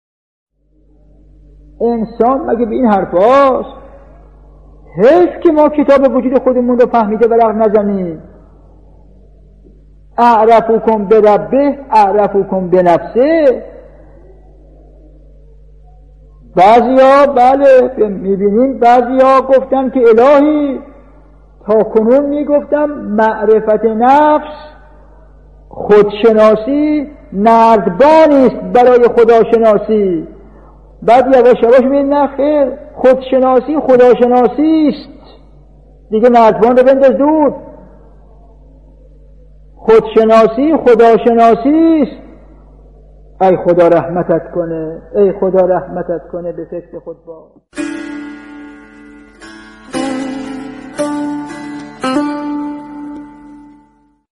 به گزارش خبرگزاری حوزه، مرحوم آیت الله حسن زاده آملی، عارف و فیلسوف جهان تشیع، در یکی از دروس اخلاق خود به موضوع «رابطه عینی خودشناسی و خداشناسی» پرداختند که تقدیم شما فرهیختگان می شود.